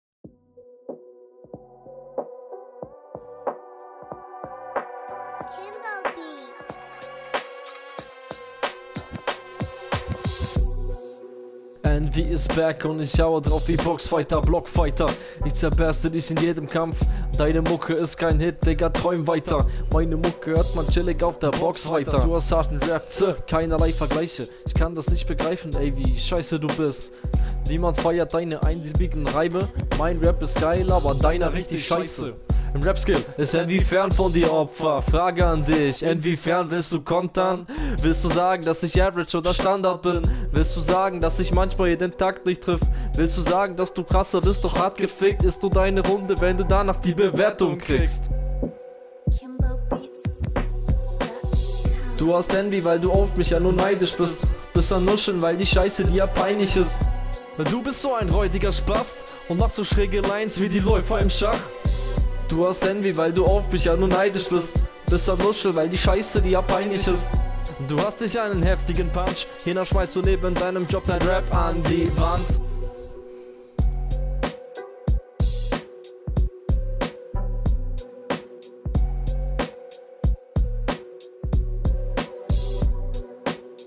Mit ner richtigen Mische wärs cooler gekommen hast gut Potential Schach Lines find ich immer …
Flow natürlich um welten besser